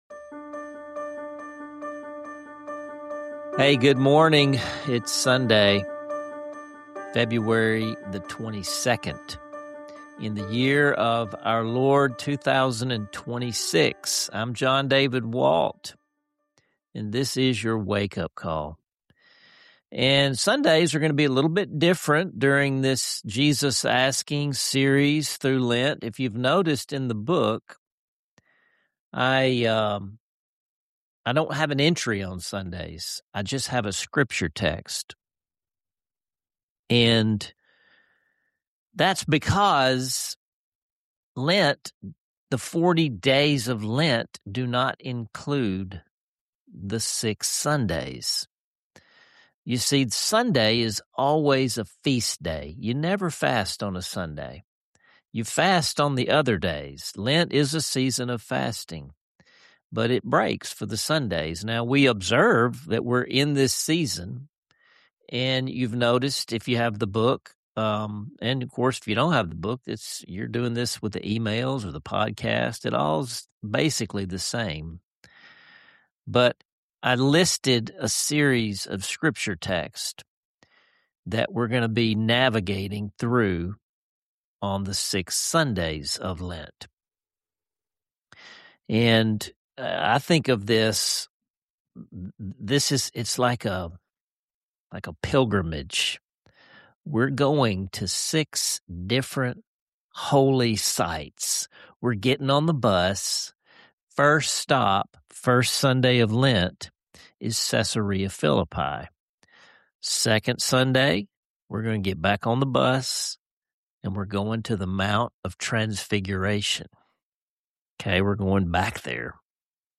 A stirring reading and meditation on Matthew 16:13-28, inviting you to hear Jesus’ pivotal questions for yourself: “Who do you say I am?”